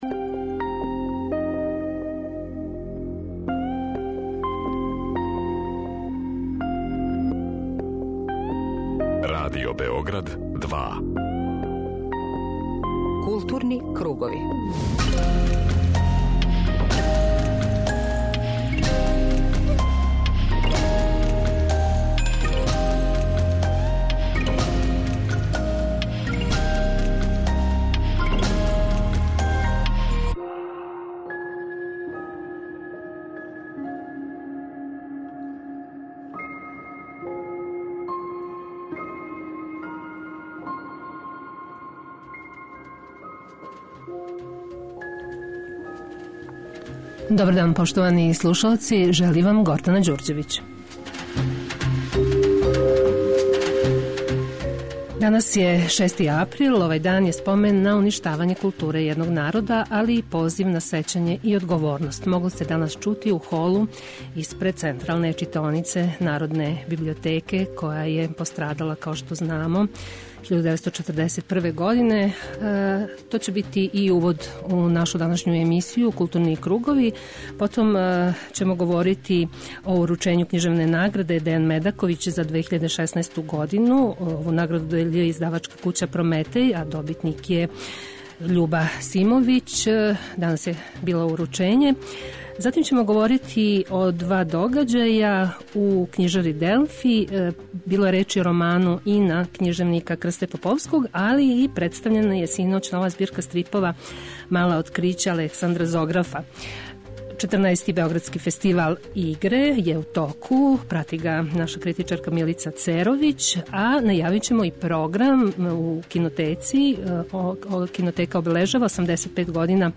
преузми : 40.37 MB Културни кругови Autor: Група аутора Централна културно-уметничка емисија Радио Београда 2.